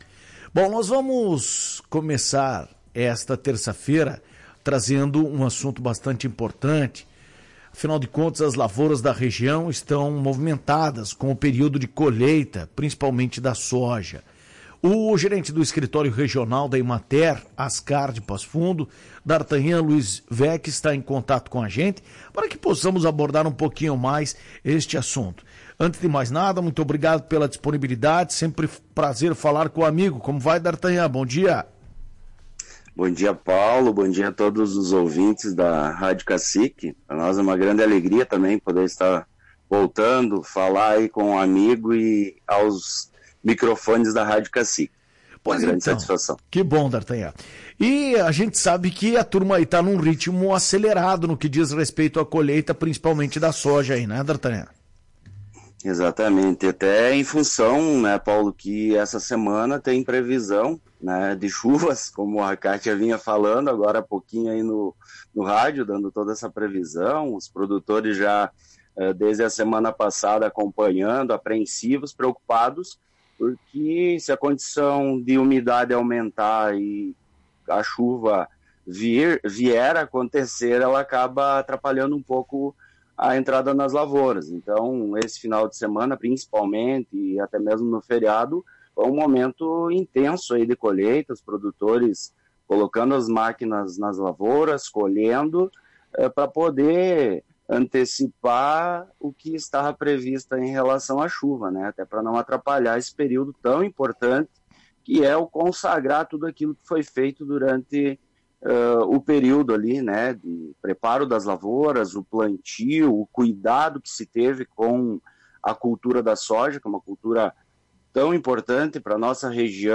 A colheita deve encerrar nos próximos dias, sendo a última cultivares do período longo. A Emater já trabalha internamente para saber o tamanho da perda desta última colheita. Ouça a entrevista completa.